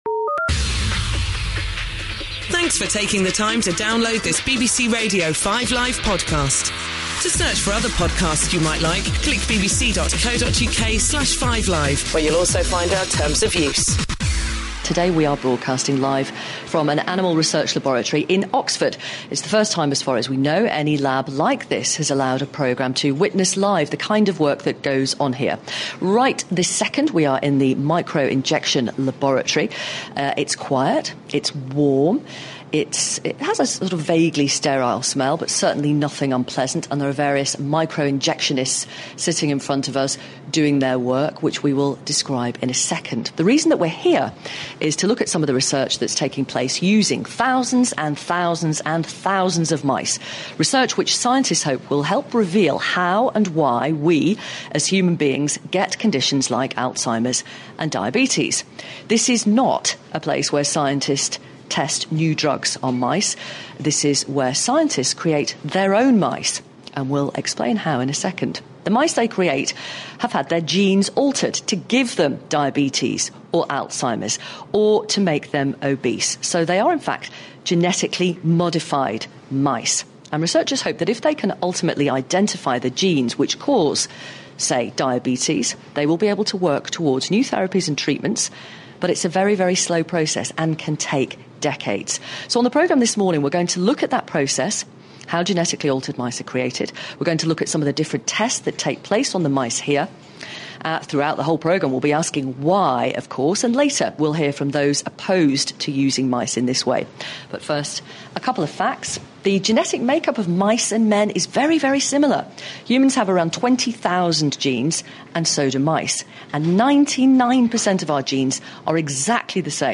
BBC Radio 5 Live visited the Mary Lyon Centre, a Medical Research Council facility south of Oxford. The programme was part documentary, and part phone-in, with a representative of Animal Aid present in addition to the scientists and lab technicians at the facility. The programme was balanced with the host, Victoria Derbyshire, correcting the Animal Aid representative’s claims.
The show pulled no punches, featuring a cervical dislocation live on air, complete with an explanation of how, although the body was kicking, the animal had experienced an instant death. It was followed by a further phone-in, which revealed a range of views.